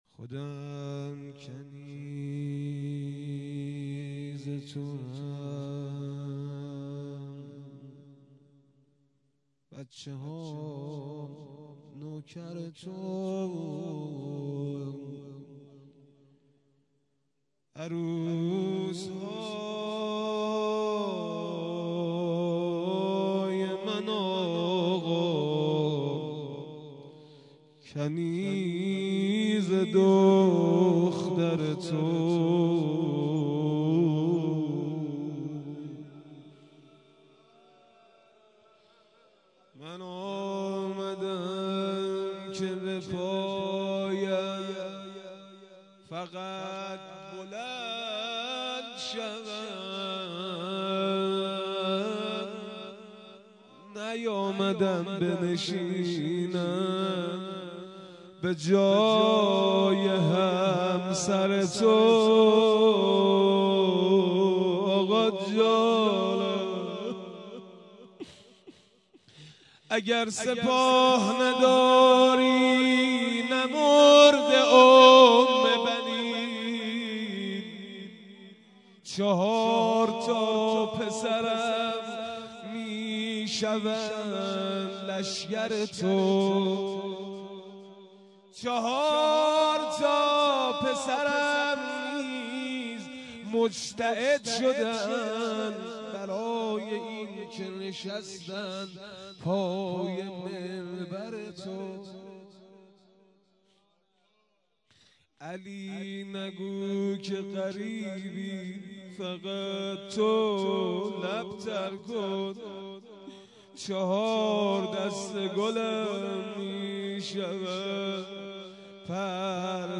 در ادامه صوت امداحی این مراسم منتشر می شود.
روضه حضرت ام البنین(س)